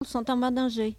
Locution ( parler, expression, langue,... )